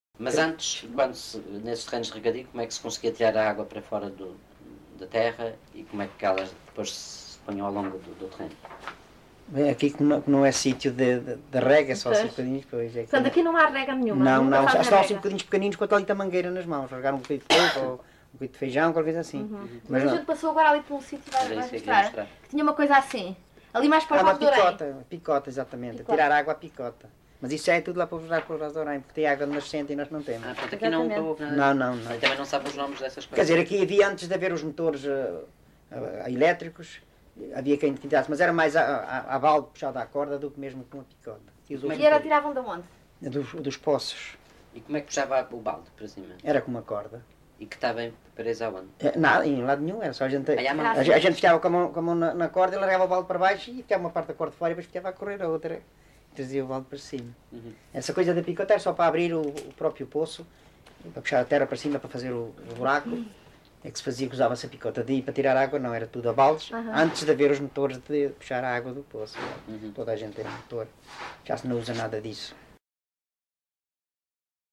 LocalidadeMoita do Martinho (Batalha, Leiria)